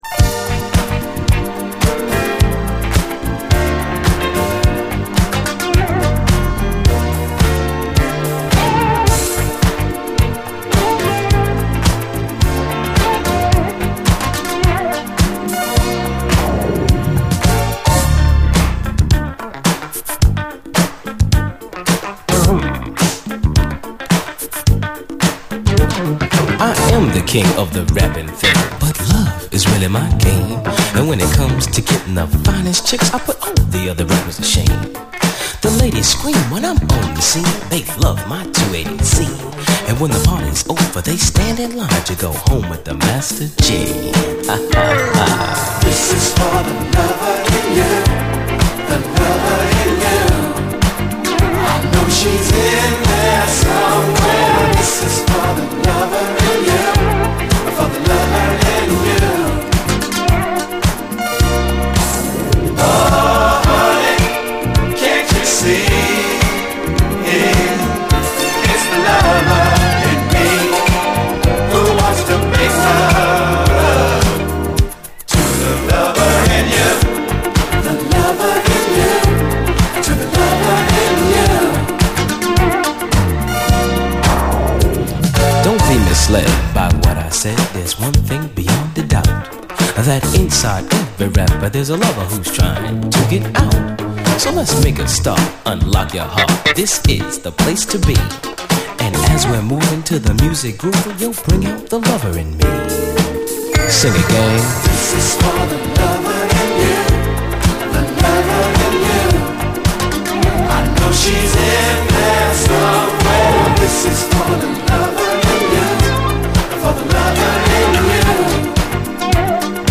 SOUL, 70's～ SOUL, DISCO, HIPHOP
永遠のメロウ・ディスコ・ラップ・クラシック！
一瞬でトロけてしまう、永遠のメロウ・ディスコ・ラップ金字塔！甘いけど踊れる、とてつもなくアーバンな一曲！